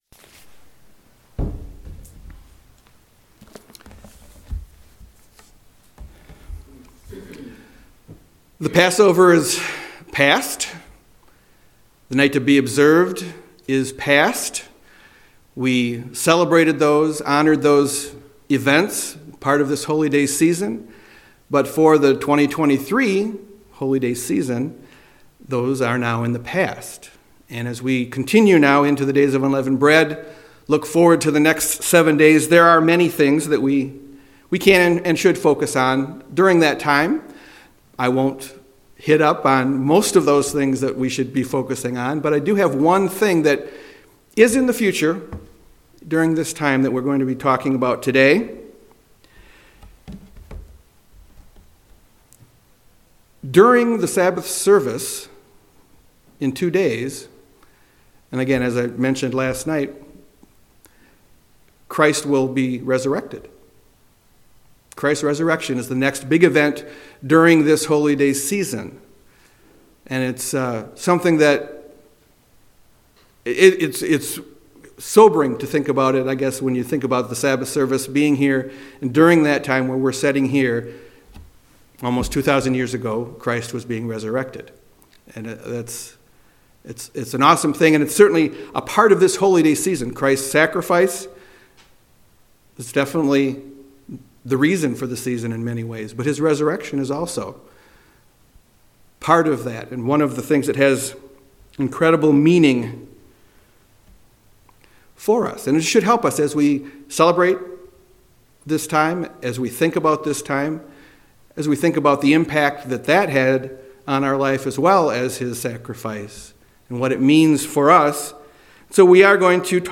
We will go through some of these in this sermon.
Given in Grand Rapids, MI